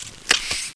launch_raise.wav